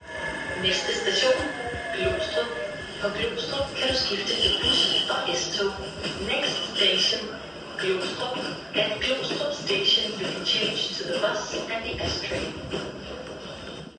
Højttalerudkald Metro og Letbane